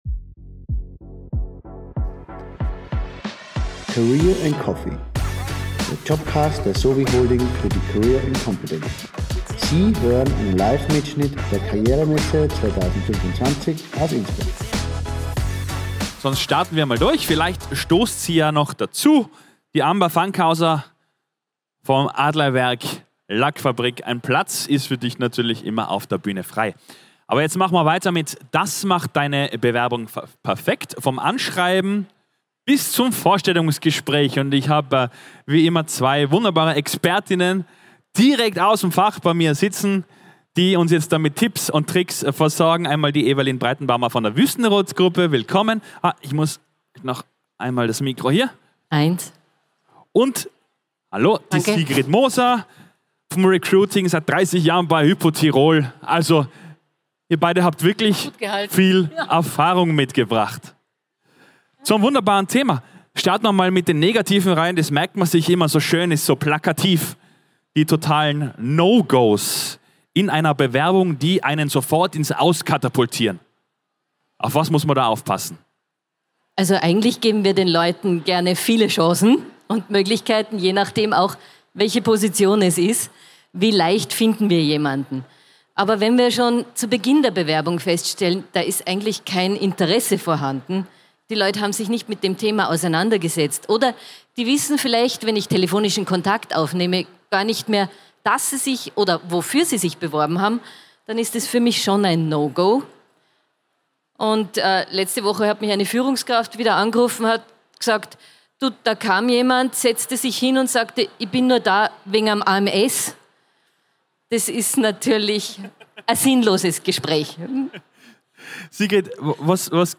Livemitschnitt #4 von der career & competence am 14. Mai 2025 im Congress Innsbruck.